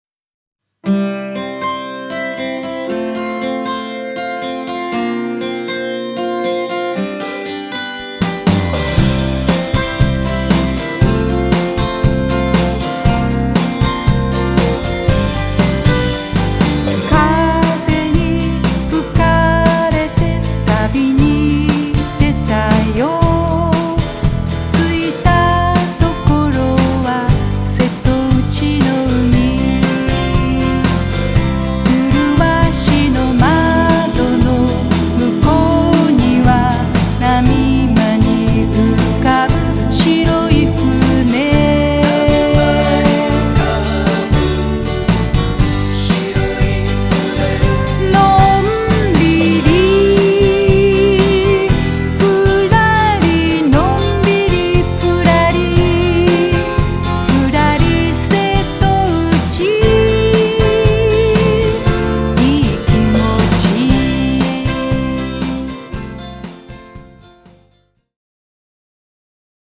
ついつい鼻歌しちゃう程、明るく親しみやすいイメージソングが生まれました。
親しみのあるメロディーを、と思って書きました。